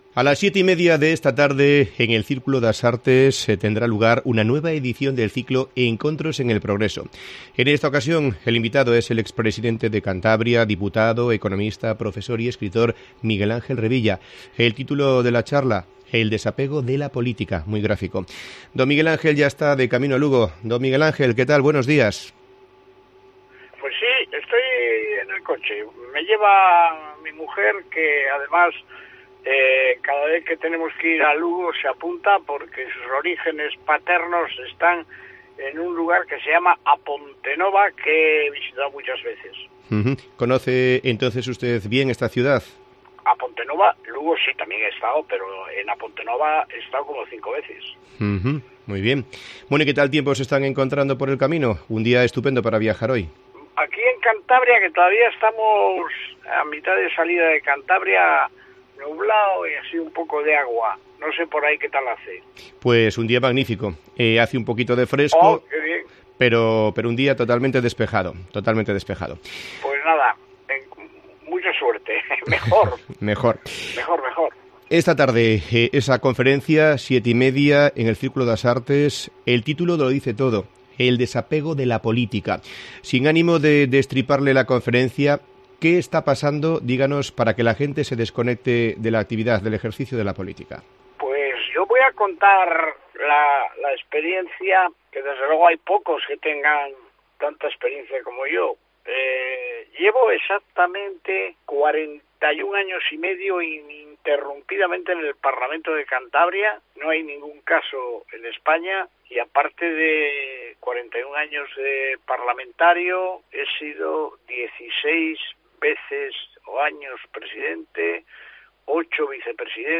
En una entrevista en COPE Lugo recuerda tres aspectos fundamentales que debe aunar el buen político: vocación, experiencia y honradez